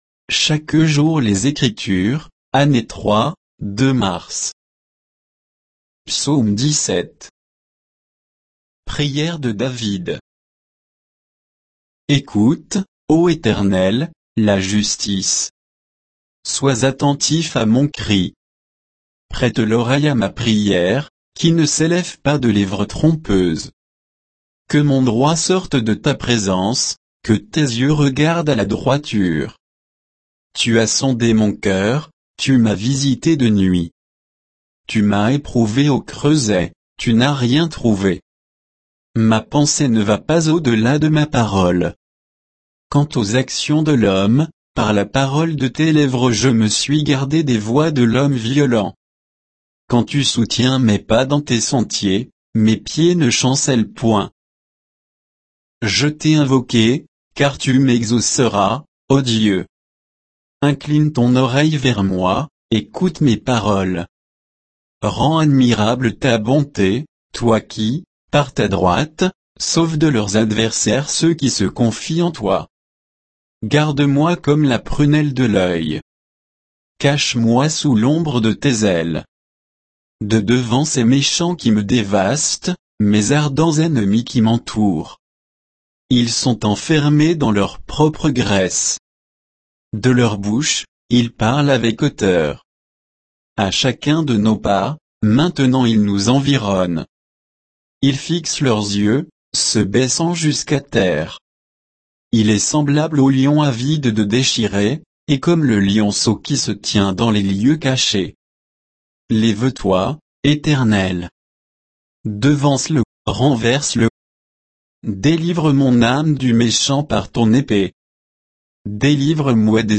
Méditation quoditienne de Chaque jour les Écritures sur Psaume 17